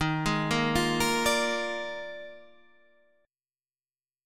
EbM7sus2sus4 chord